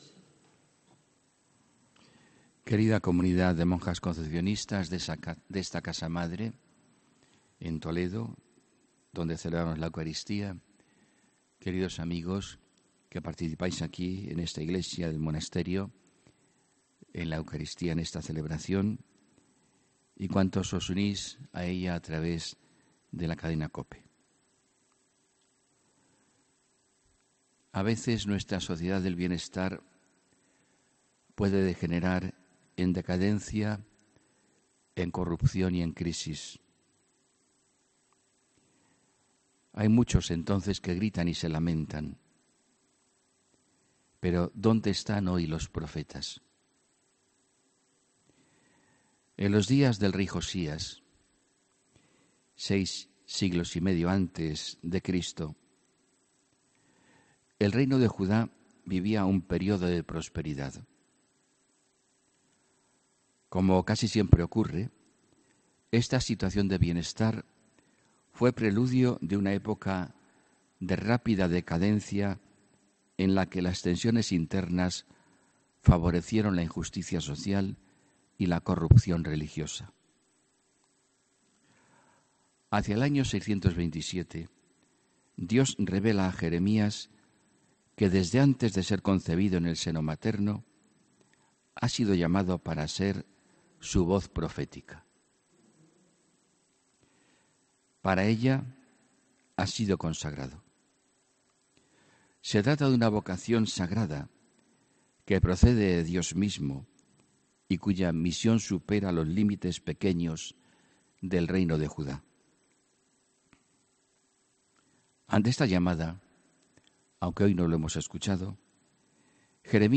HOMILÍA 3 DE FEBRERO 2019